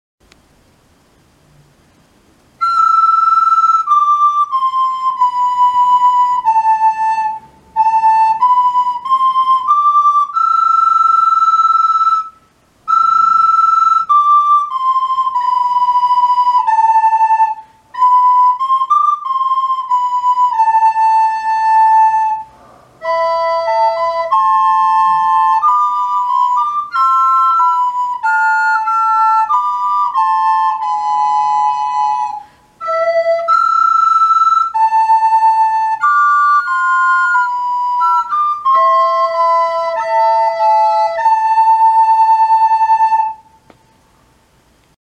５年生 音楽科『小さな約束』 リコーダー音源と動画
小さな約束 1のメロディが少し強め 小さな約束 2のメロディが少し強め